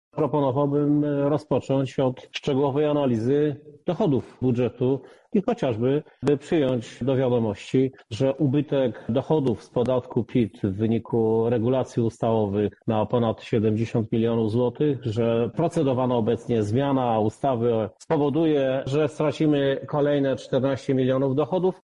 Trwa XXIII sesja Rady Miasta Lublin. Między innymi odbyło się już pierwsze czytanie projektu uchwały budżetowej na 2021 rok.
Prezydent Krzysztof Żuk zwrócił jednak uwagę na inną kwestię: